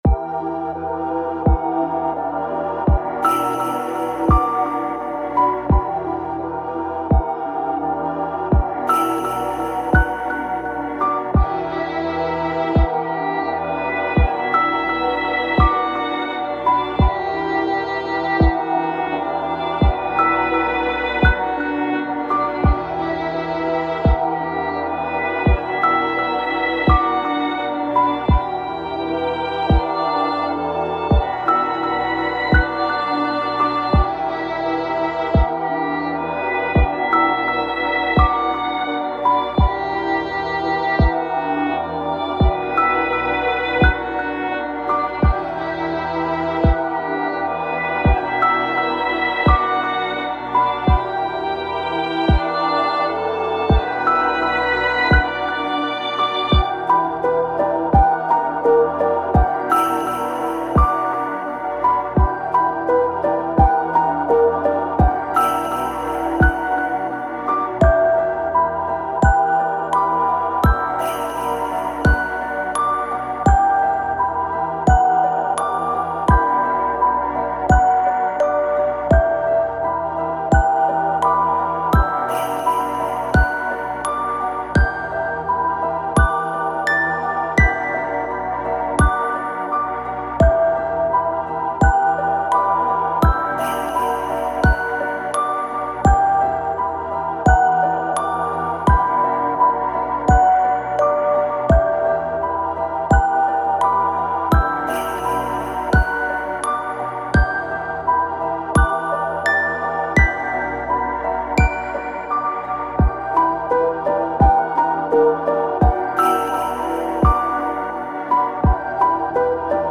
💬誰からも忘れられてしまったもの…そんな悲しいイメージを曲にしました。
少し不気味さもあるかもしれません。